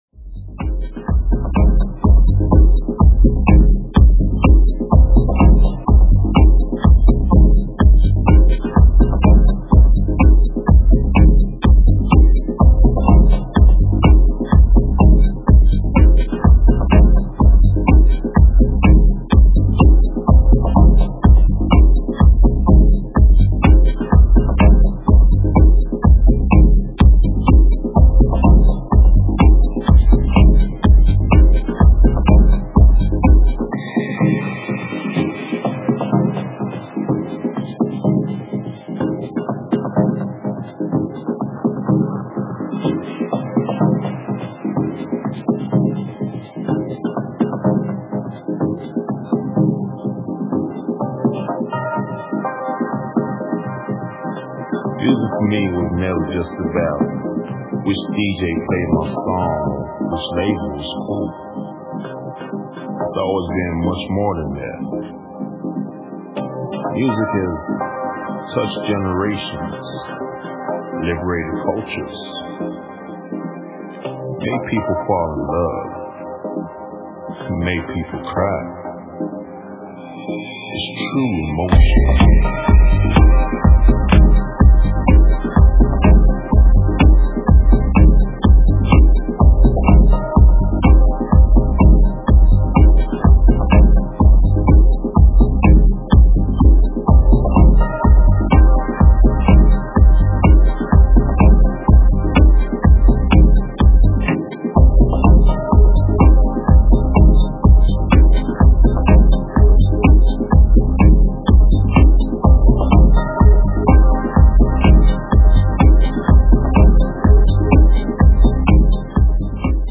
a lavish, unhurried exploration